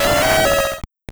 Cri de Donphan dans Pokémon Or et Argent.